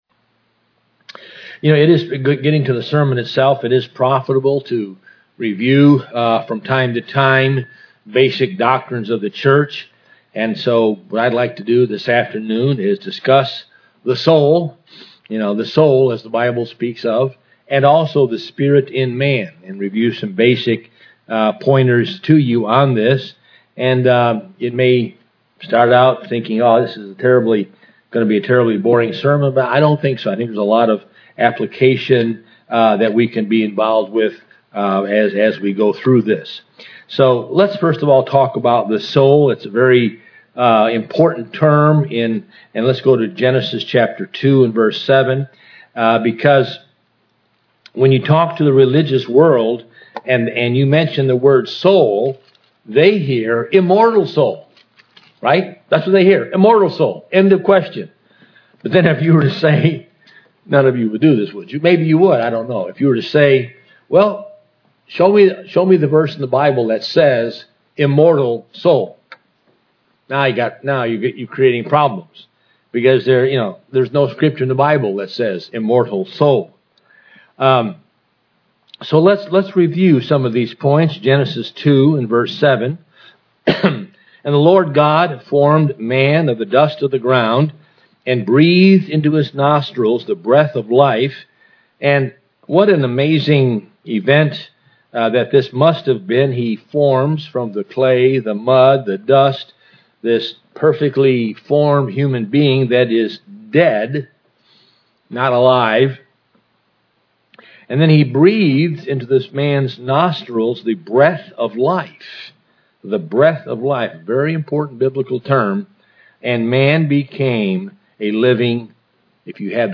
This sermon reviews the scriptures which explain the soul. It also explains the scriptures describing the spirit in man.